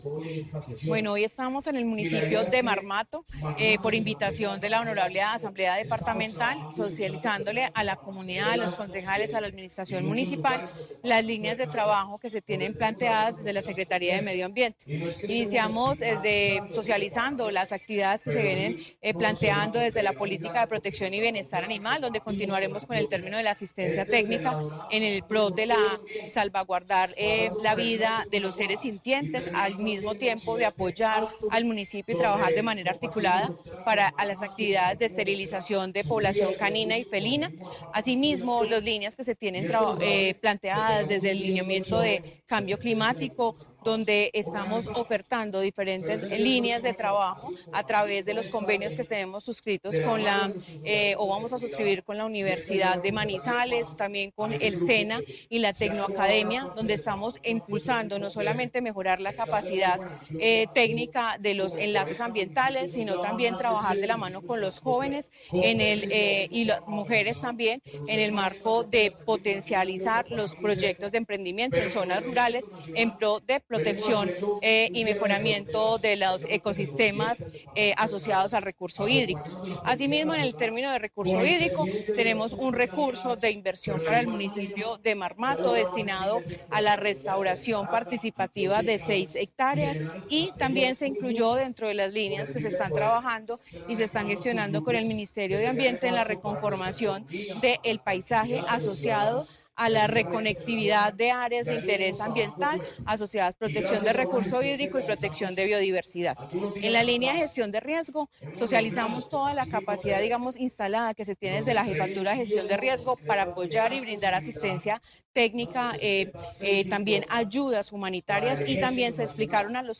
Secretaria de Medio Ambiente de Caldas, Paola Andrea Loaiza.